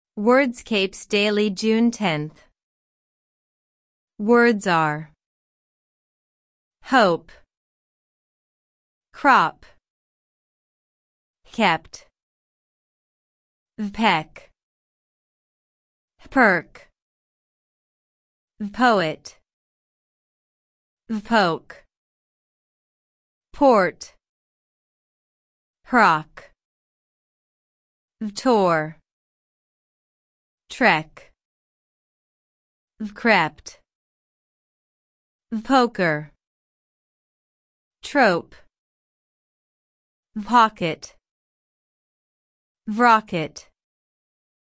On this page you’ll get the full Wordscapes Daily Puzzle for June 10 Answers placed in the crossword, all bonus words you can collect along the way, and an audio walkthrough that can read the answers to you at the speed you like while you’re still playing.